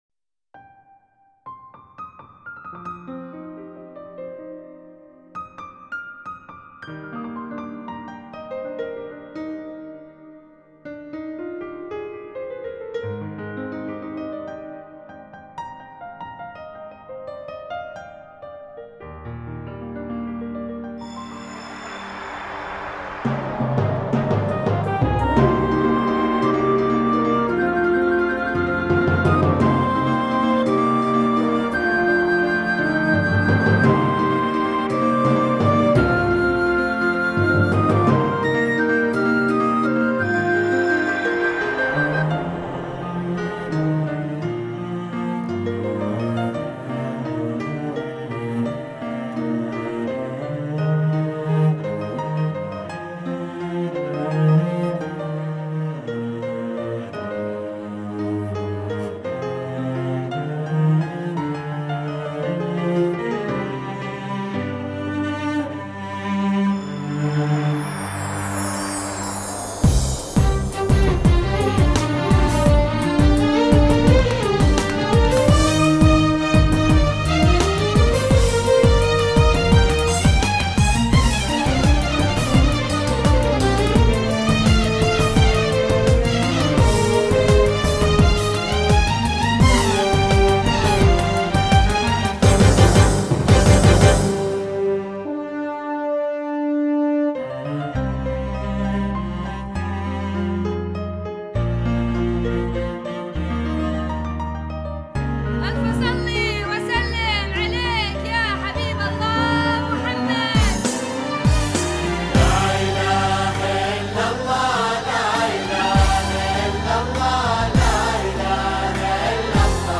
زفة كوشة بدون موسيقى